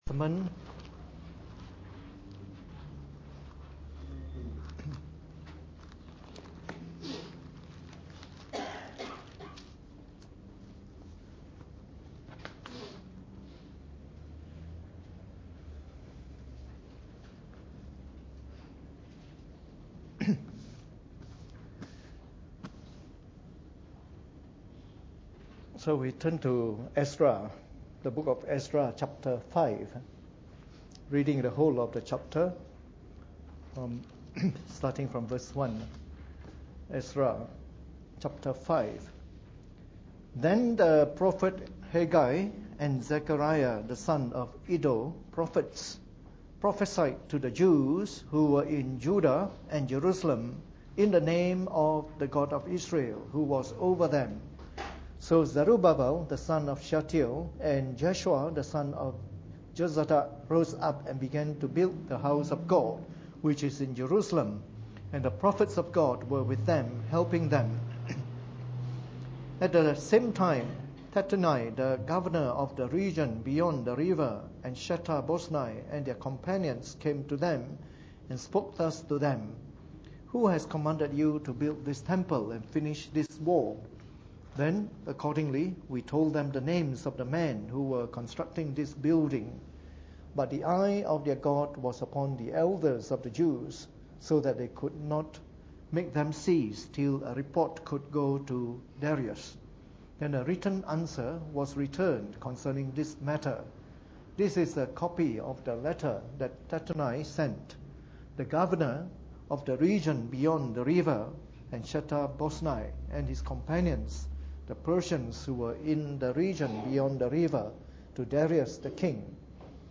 Preached on the 19th of February 2014 during the Bible Study, from our series of talks on the Book of Ezra.